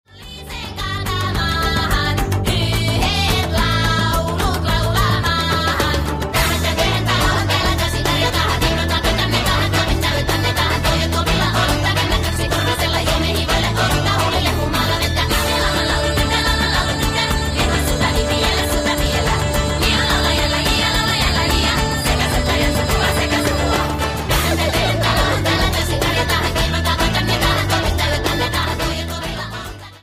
A more happy song